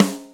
share-drum.wav